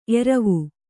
♪ eravu